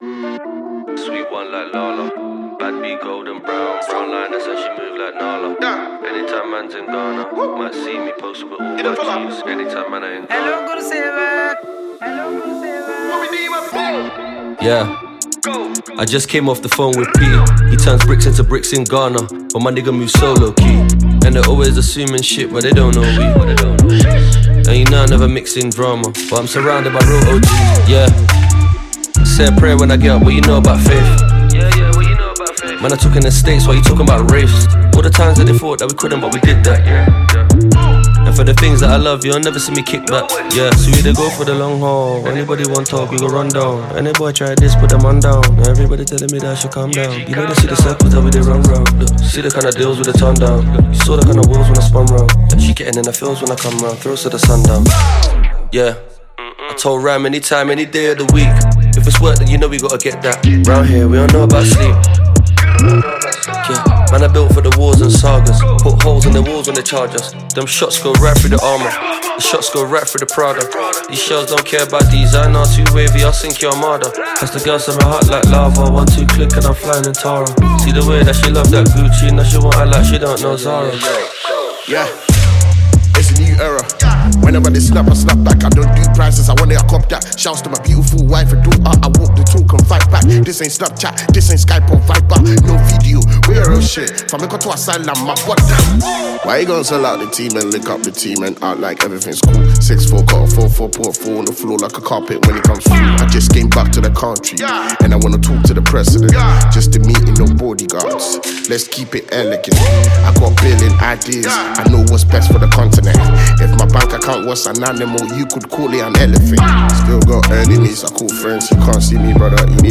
UK-Based Ghanaian rapper and singer
award-winning Ghanaian rapper